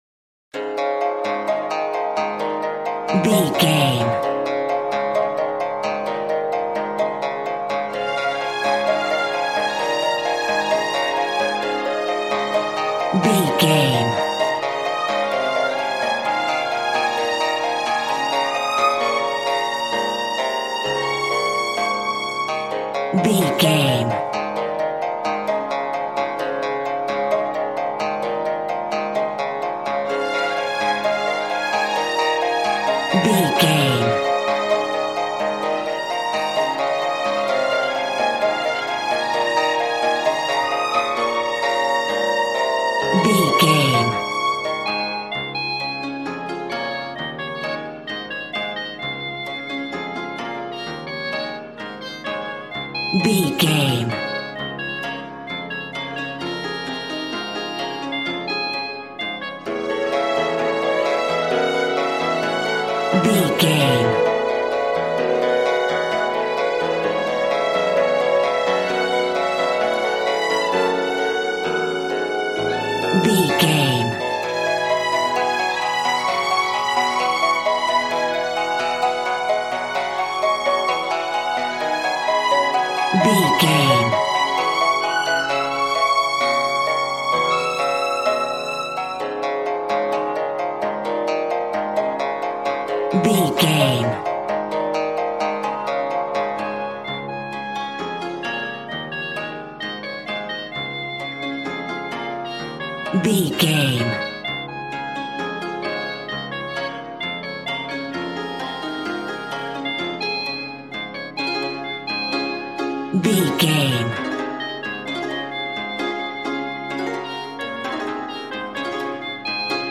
Ionian/Major
D♭
smooth
conga
drums